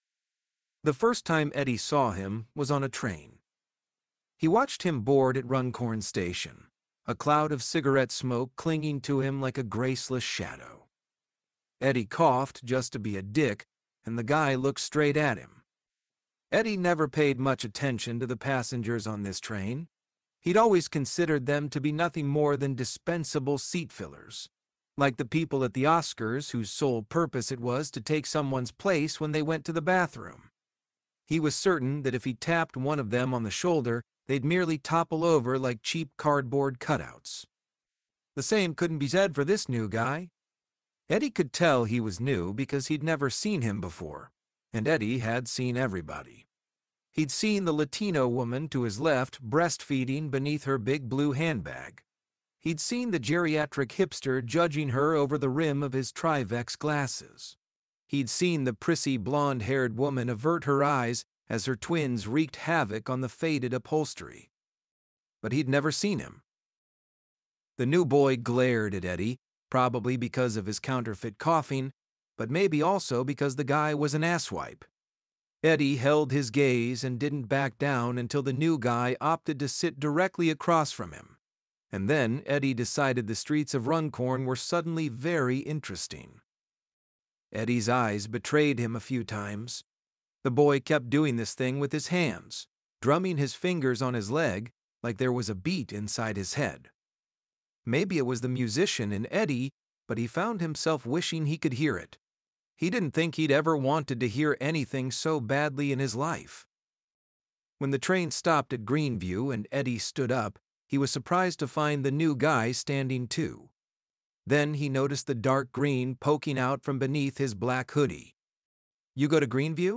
'Boys on a Train' Becomes an Audiobook! (For US Readers)
After a long wait, Boys on a Train is now an audiobook, available to listen to on Amazon (US).